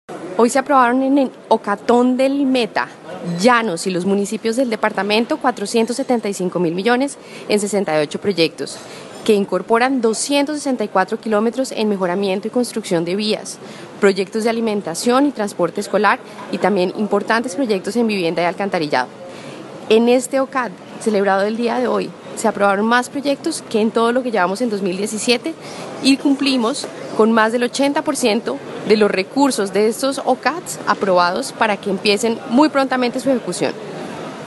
viceministra-declaraciones